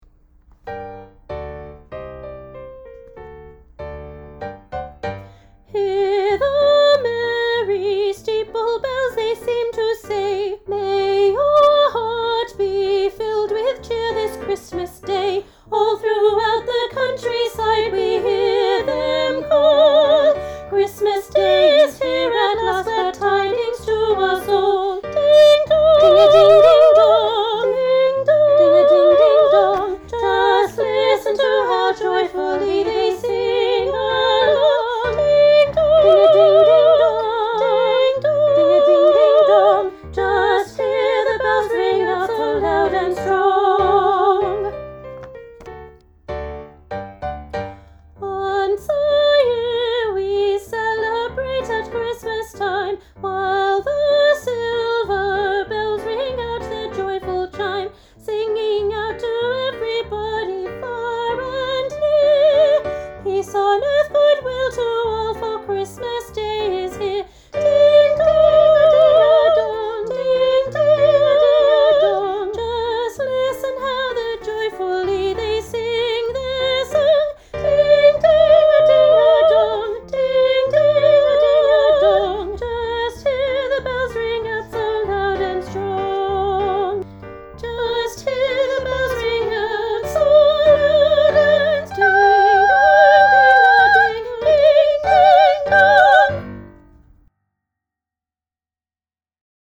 Junior-Choir-Ding-Dong-Soprano.mp3